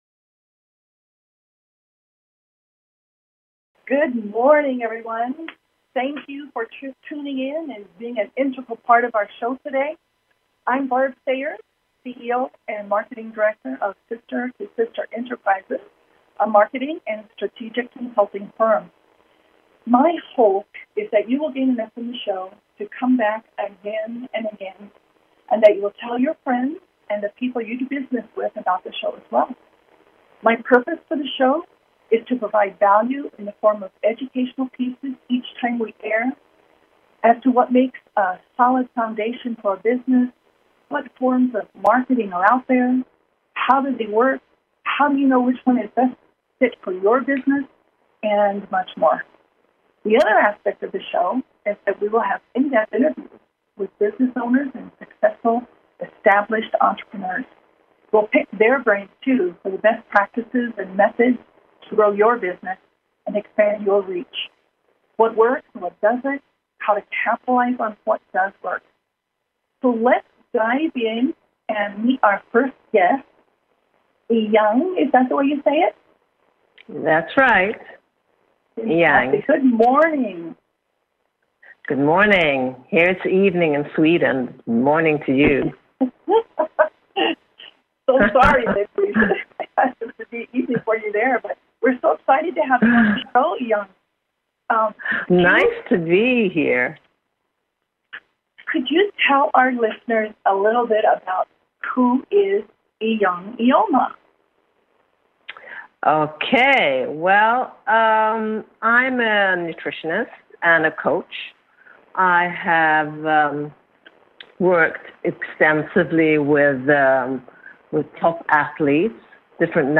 Bizz Buzz is a talk show, crafted to provide unique, powerful marketing techniques that can positively impact your businesses bottom line, in sales and customer acquisition.
Call-ins encouraged!